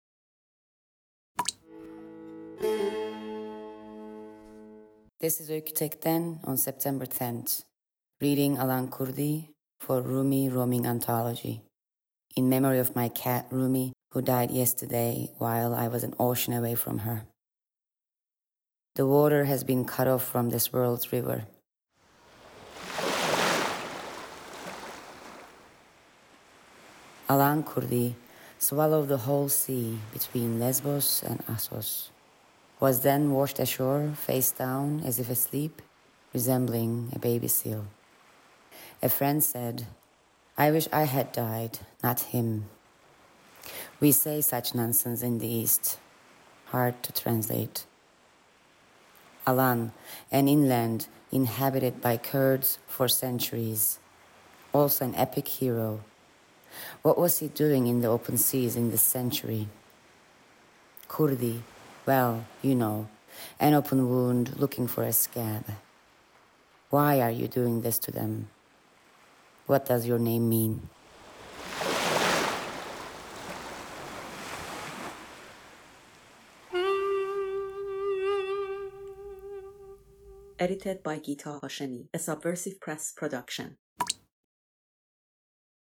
Contemporary poetry, Rumi roaming, Syria, Kurdish, Border crossing